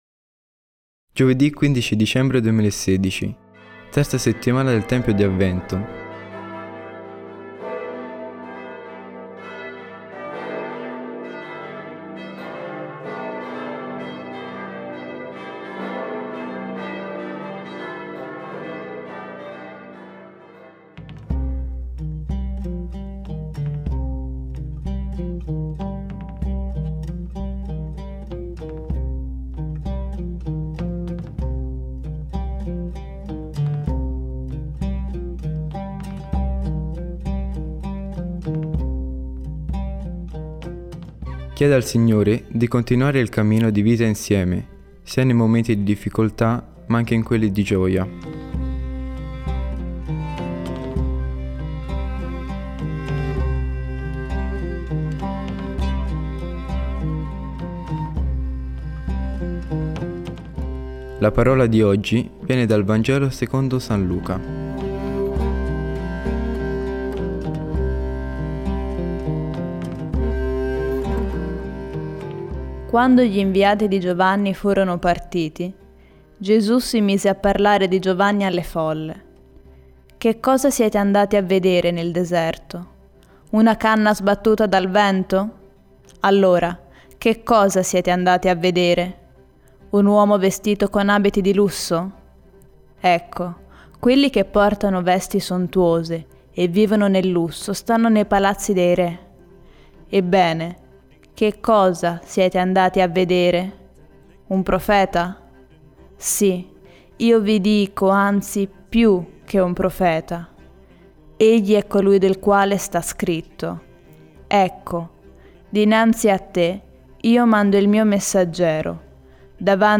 Musica: Dyngyldai – Dayazell